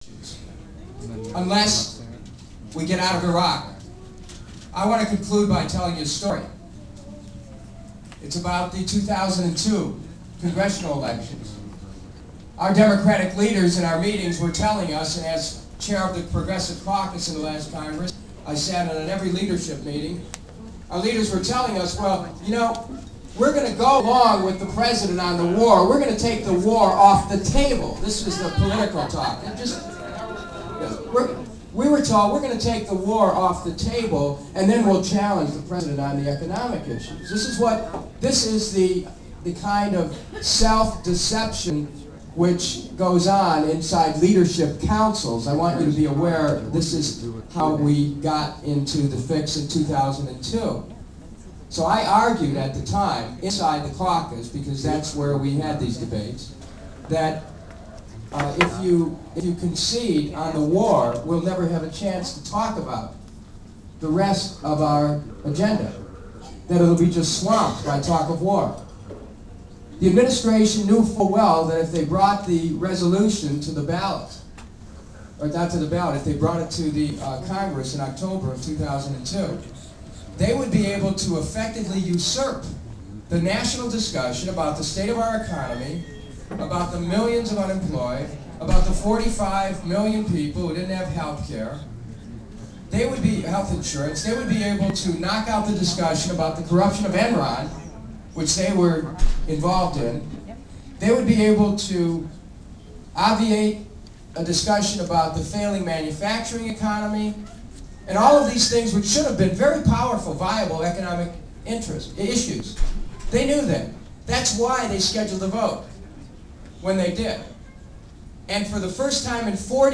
Pictures I took at the Kucinich Campaign Party in Washington, DC. plus audio files (.wav)